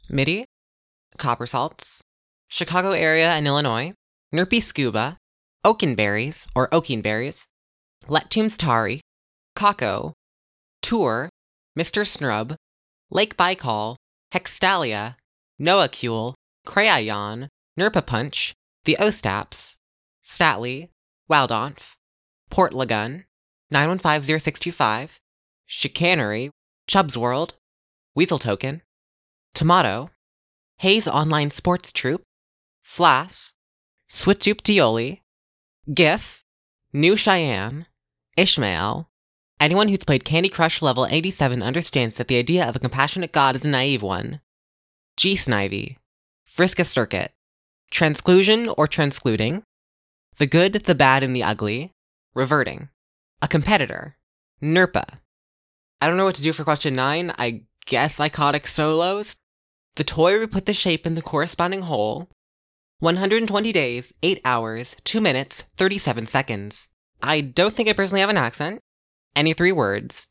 Recordings of ourselves answering those questions.